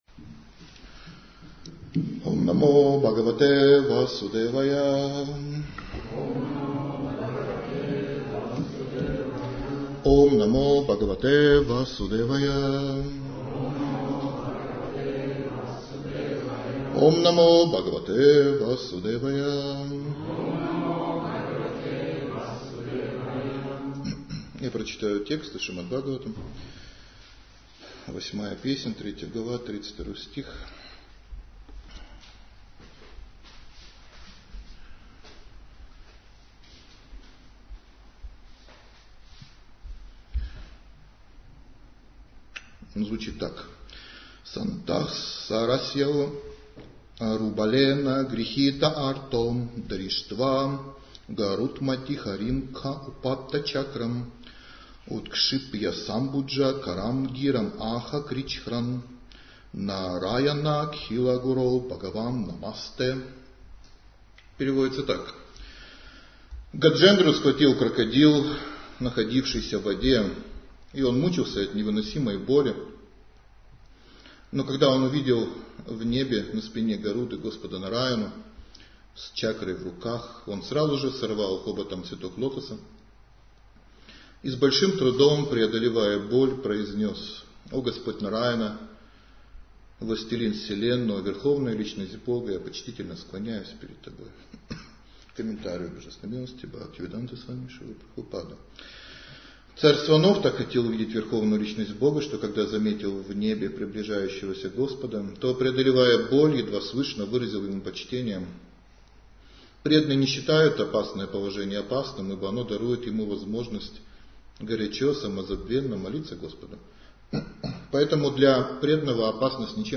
Лекция